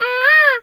bird_peacock_squawk_10.wav